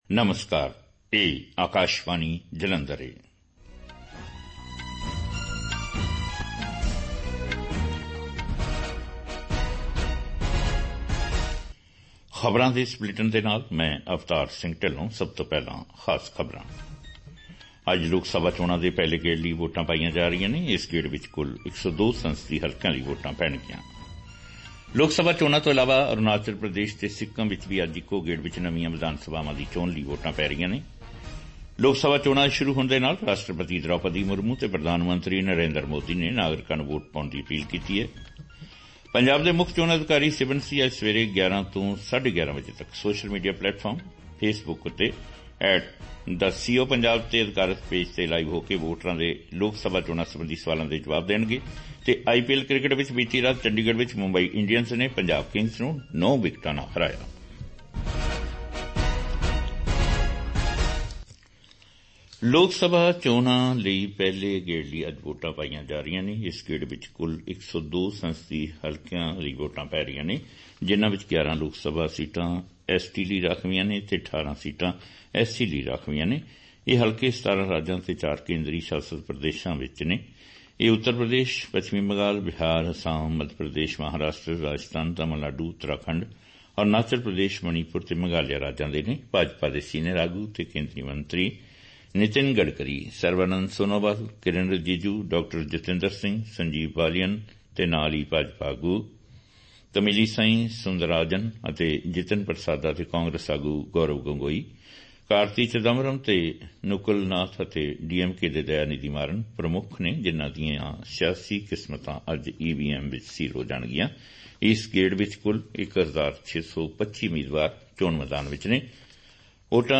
punjabi-news-b.mp3